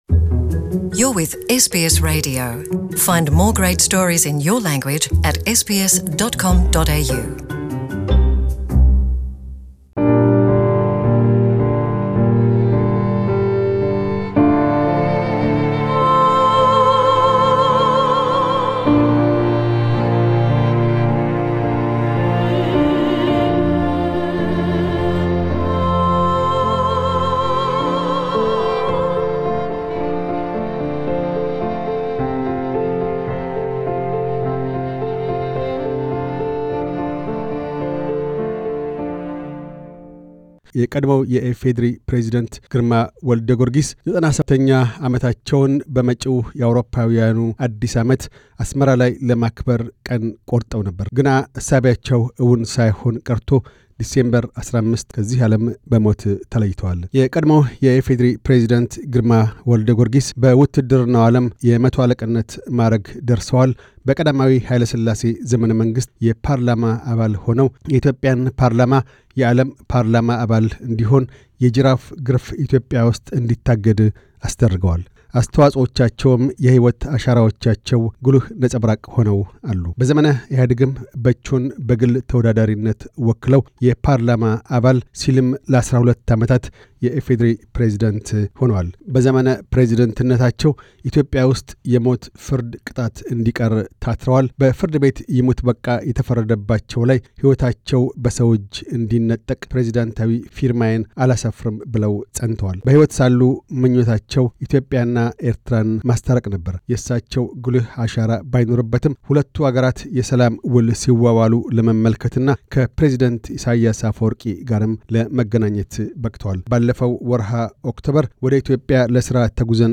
ለዛሬው ግና ጃኑዋሪ 2 – 2014 ክቀድሞው ፕሬዚደንት ጋር ያደረግነውን ቃለ ምልልስ መዘከሪያ ይሆን ዘንድ ይዘን ቀርበናል።